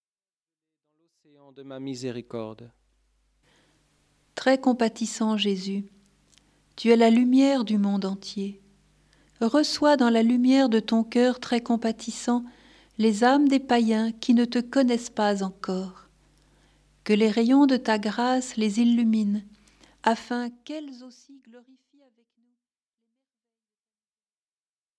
Prières, chants, et enseignements.